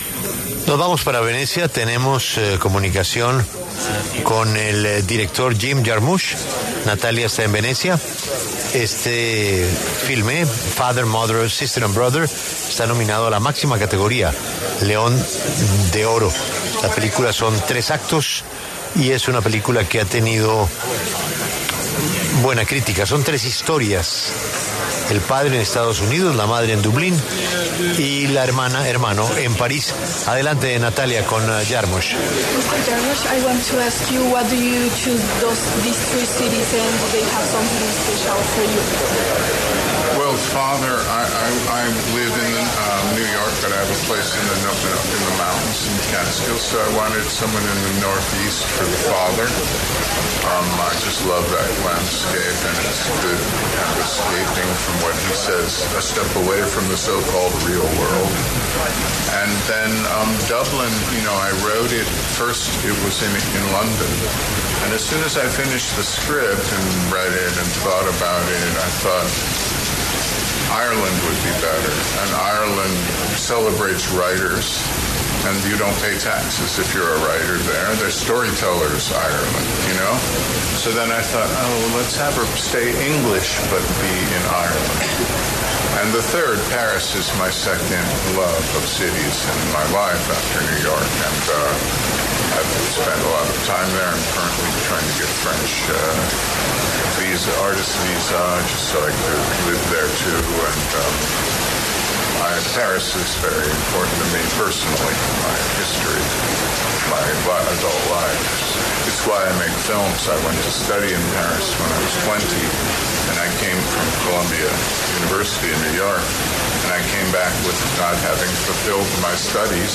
En La W, el director Jim Jarmusch habló desde el Festival de Cine de Venecia.
Desde el Festival de Cine de Venecia 2025, Julio Sánchez Cristo conversó en La W con el cineasta Jim Jarmusch, quien dirigió la película ‘Father, Mother, Sister, Brother’ que está nominada al León de Oro.